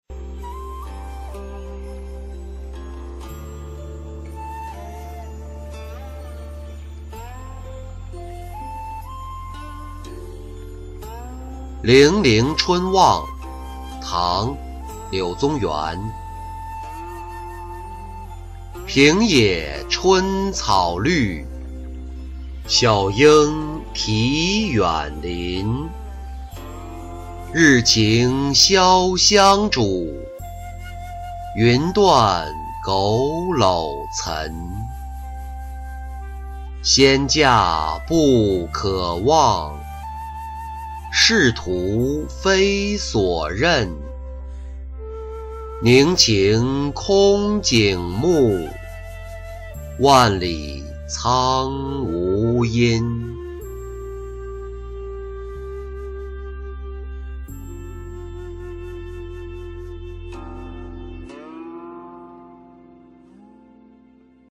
零陵春望-音频朗读